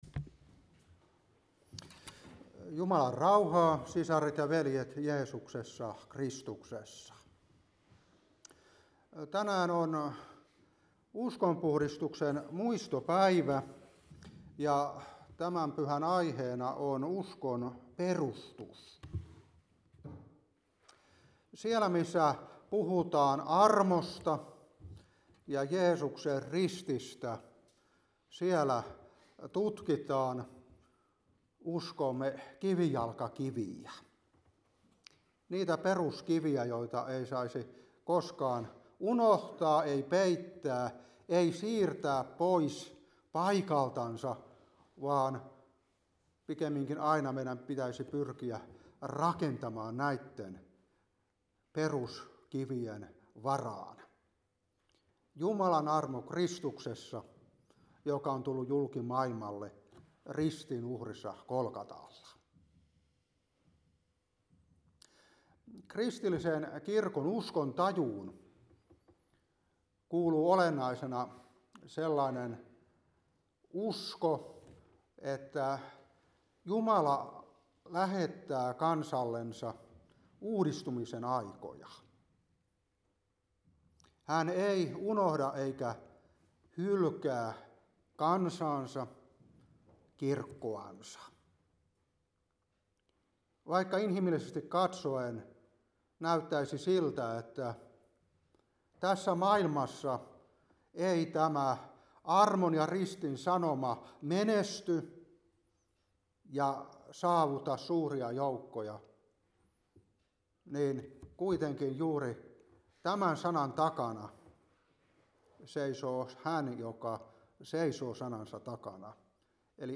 Seurapuhe 2024-10. Ps.119:46. 1.Kor.3:11.